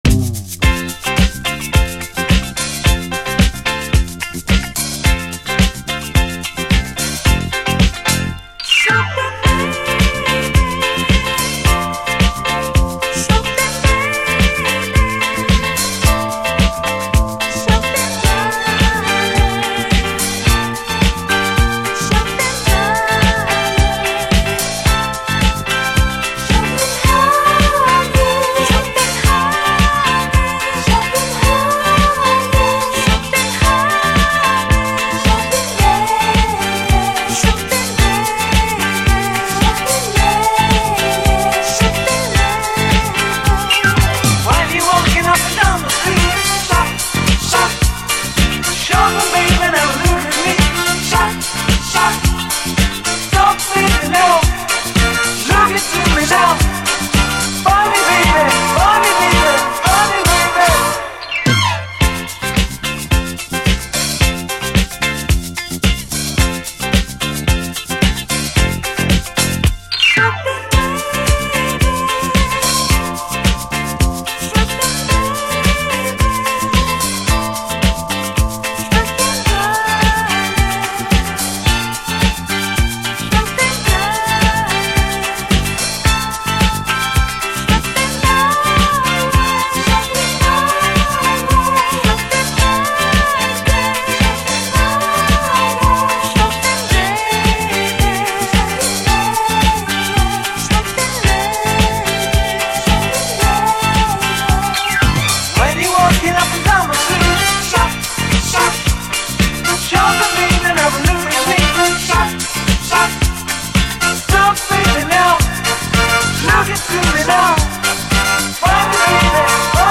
SOUL, 70's～ SOUL, DISCO
ミュンヘン発のズンドコ・ユーロ・ディスコ！
フワフワと浮遊するコーラスの質感がいかにもユーロ産という感じです！